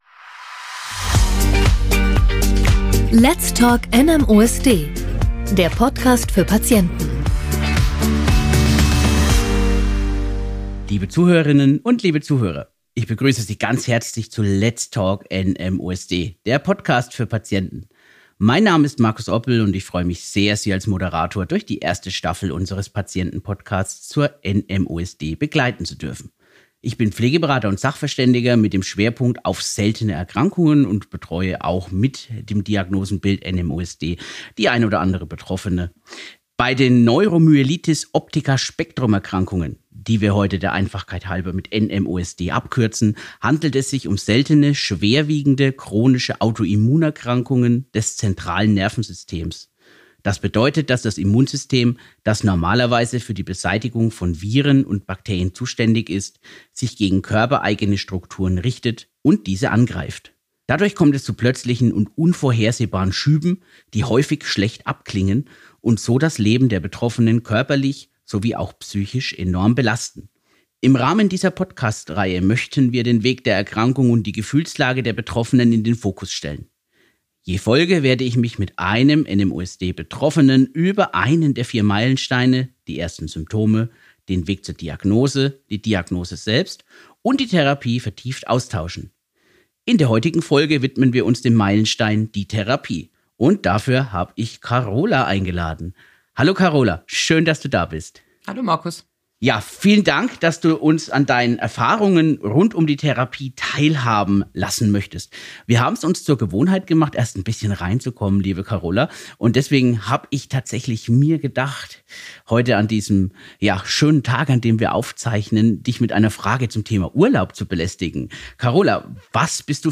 NMOSD Betroffene